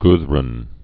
(gthrn)